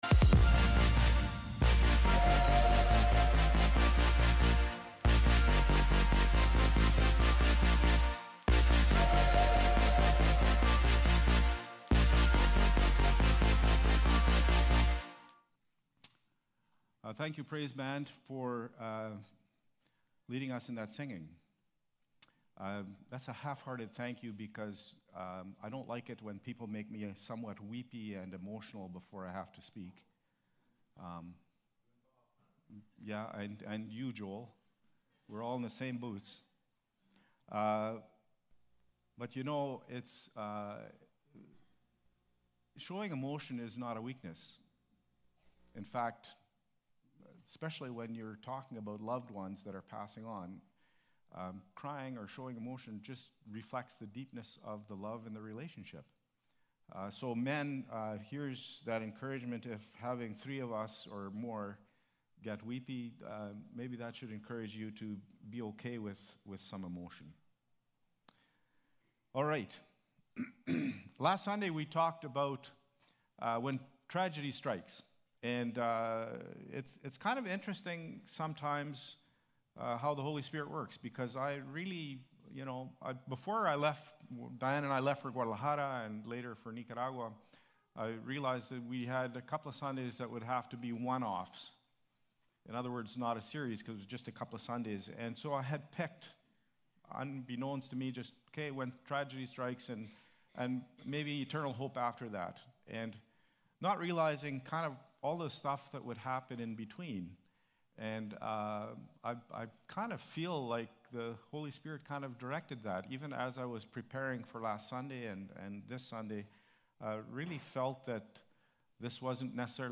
Nov-16-Worship-Service.mp3